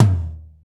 TOM R B L0OL.wav